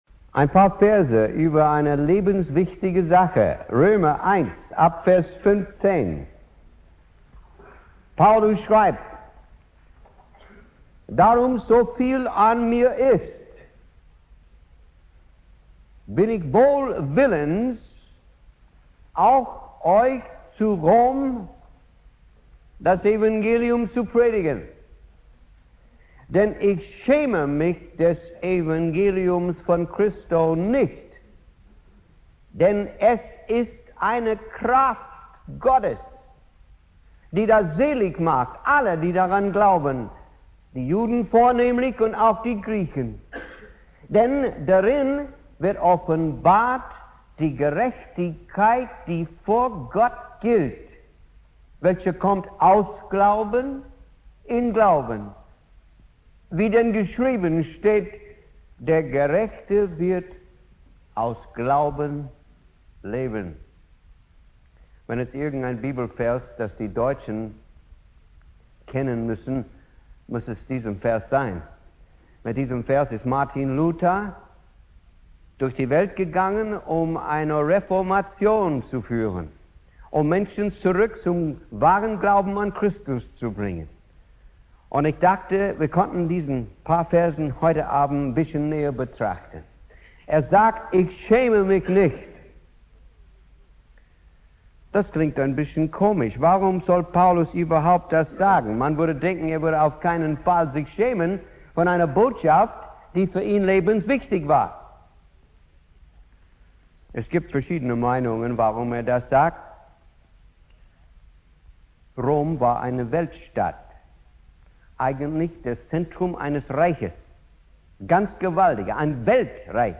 Predigt
4. Abend der Evangelisation